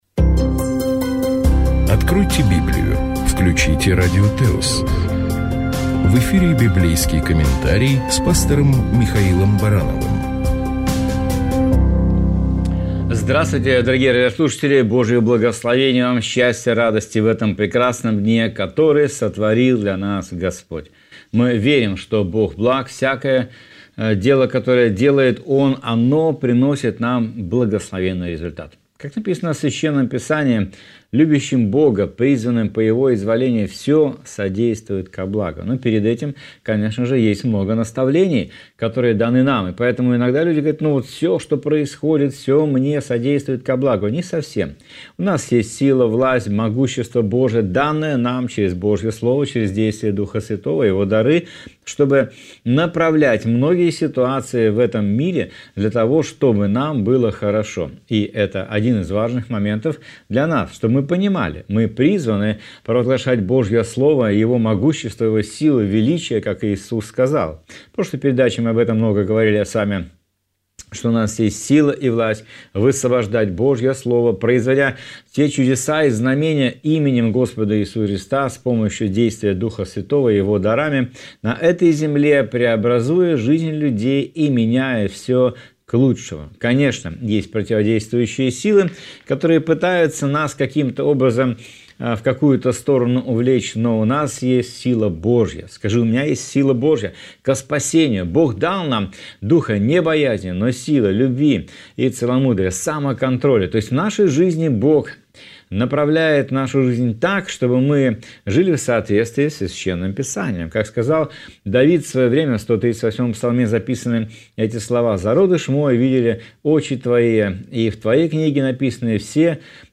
Чтение Библии